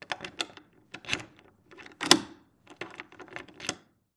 Lock Deadbolt Unlock Key Sound
household
Lock Deadbolt Unlock Key